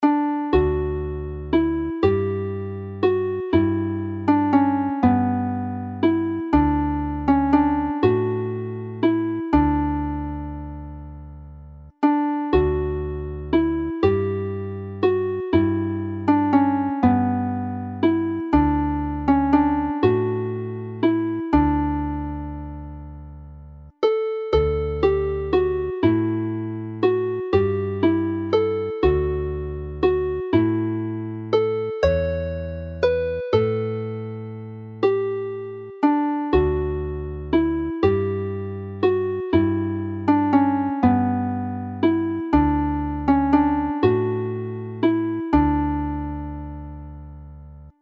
In the winter season (D major)